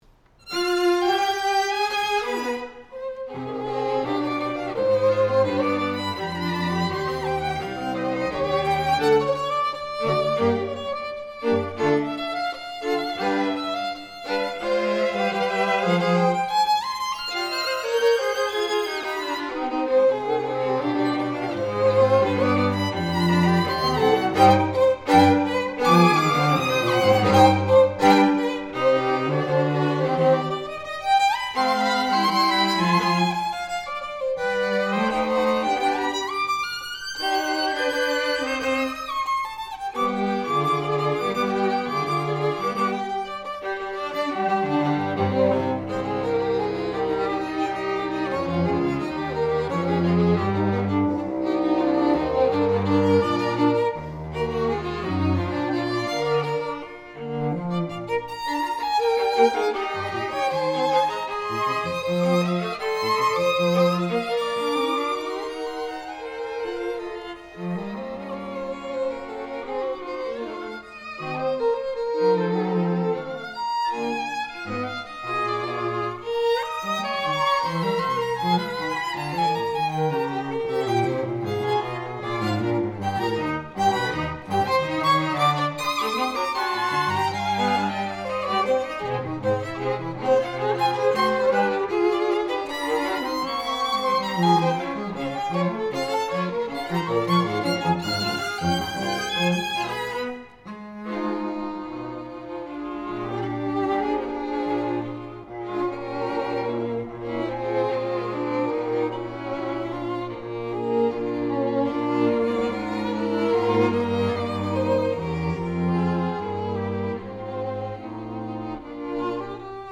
Chamber Groups